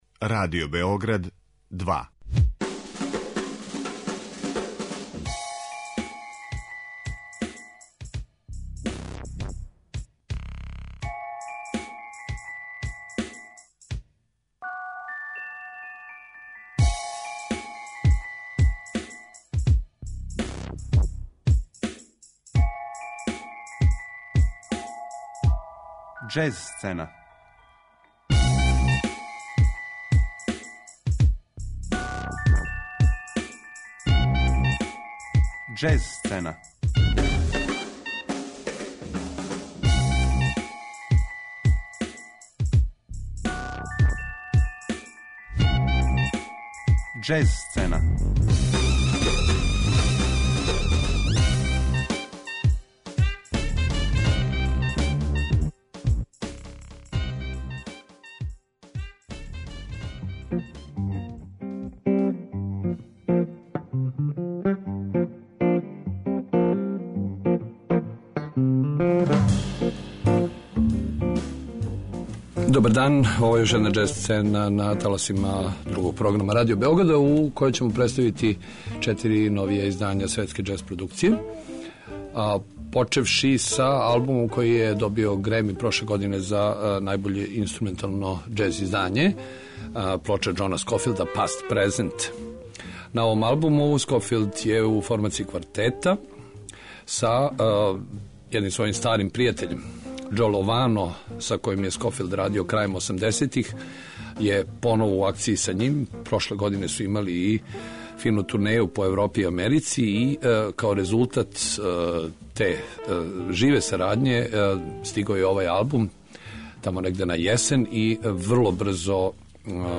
Логан Ричардсон један је од најзапосленијих америчких саксофониста, једнако успешан и као сајдмен (састави Кристијана Скота, Џералда Клејтона и Џејсона Морана) и лидер, објављујући трећи албум за чувену кућу Blue Note.
Јапански продуцент Тошио Мацура, који је светску славу стекао са иновативним електро-џез саставом United Future Organisation, сада има ново чедо - квартет Hex - на истом путу мешања електронике и џез израза.